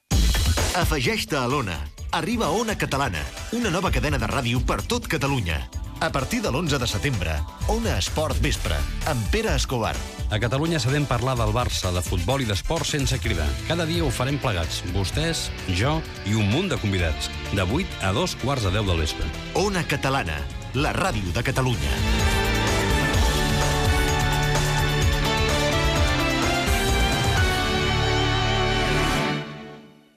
Promoció del programa Gènere radiofònic Esportiu